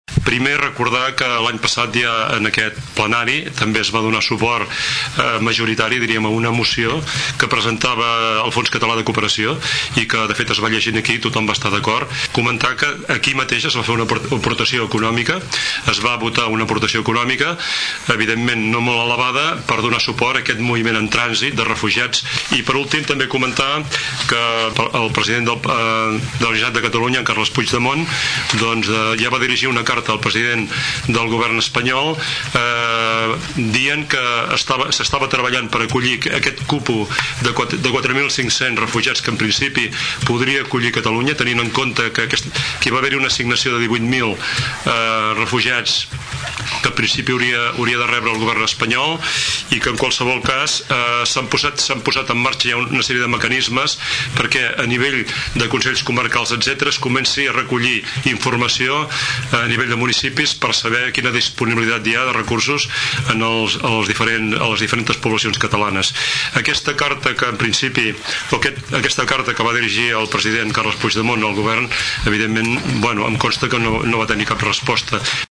El regidor d’atenció social i serveis a la persona, Àngel Pous recordava  la implicació del consistori amb els refugiats, tot recordant les mesures que s’han engegat a nivell local i comarcal davant aquesta problemàtica.